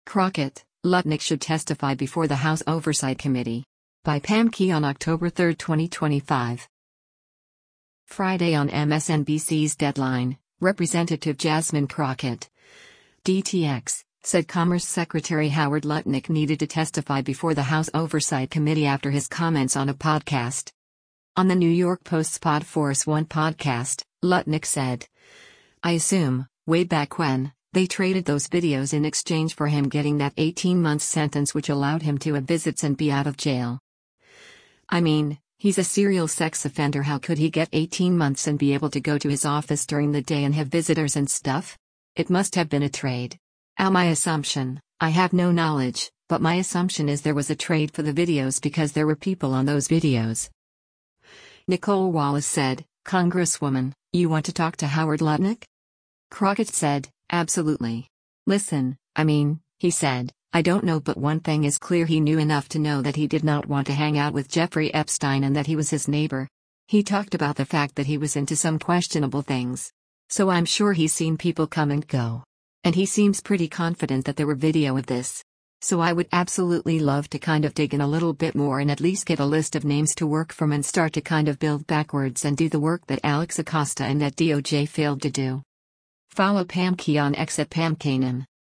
Friday on MSNBC’s “Deadline,” Rep. Jasmine Crockett (D-TX) said Commerce Secretary Howard Lutnick needed to testify before the House Oversight Committee after his comments on a podcast.